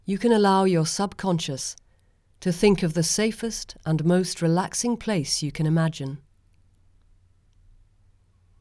Female voice quality - equalisation advice appreciated - Page 5 - Windows - Audacity Forum
With (so I’m assured) exactly the same setup as before, the same curious boomy room tone seems to have returned. Possibly it’s emphasised by the very low recording level, but then again possibly not.
The ‘boom’ doesn’t sound egregious to my ear. I suspect it’s part proximity effect, part room echo.